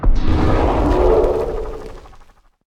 Minecraft Version Minecraft Version latest Latest Release | Latest Snapshot latest / assets / minecraft / sounds / mob / evocation_illager / prepare_attack2.ogg Compare With Compare With Latest Release | Latest Snapshot
prepare_attack2.ogg